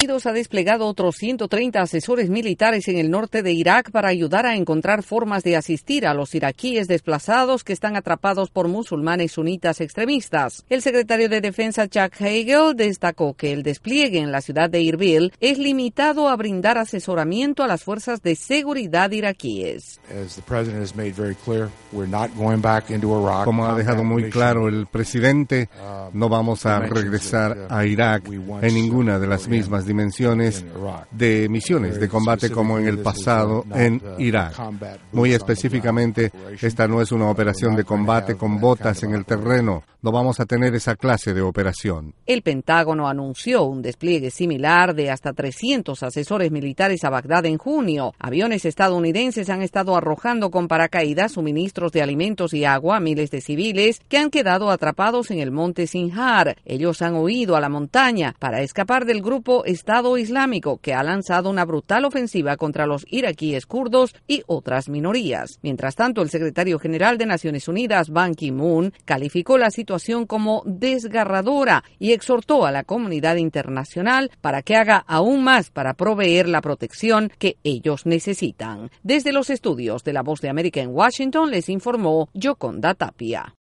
Más de un centenar de asesores estadounidenses fueron enviados a Irak para apoyar las tareas humanitarias con los desplazados. Desde la Voz de América en Washington DC informa